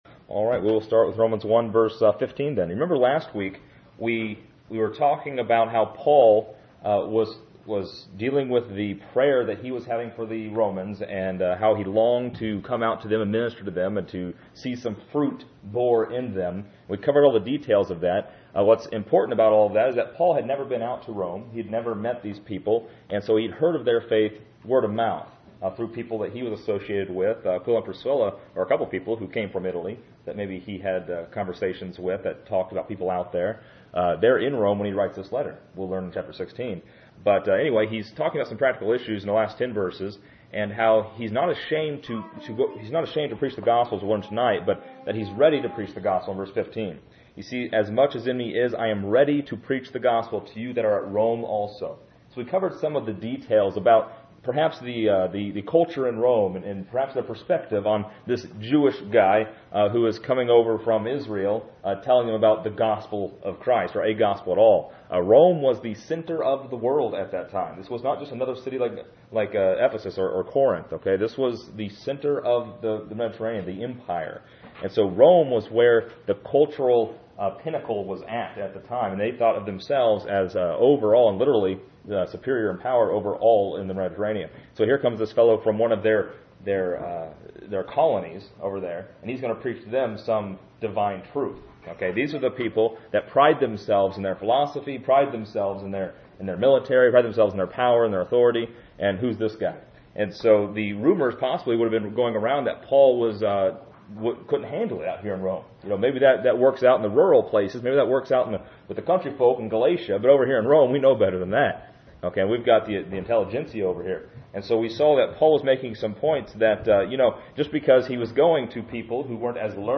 This lesson is part 5 in a verse by verse study through Romans titled: The Power of God.